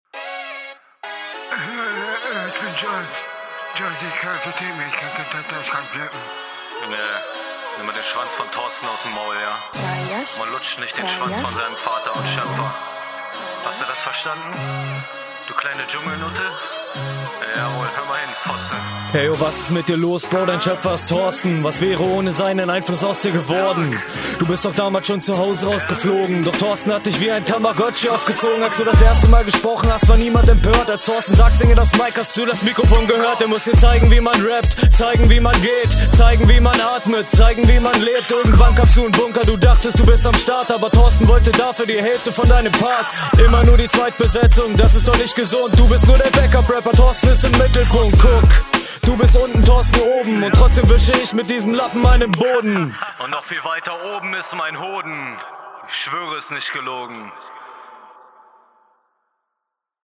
Flow imho bis zum drop sehr holprig, danach bist du routinierter unterwegs ist vielleicht verpöhnt …
S laute sind schon bissl miese jao